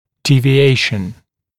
[ˌdiːvɪ’eɪʃ(ə)n][ˌди:ви’эйш(э)н]отклонение (от нормы), девиация